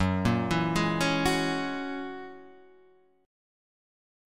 F#9 chord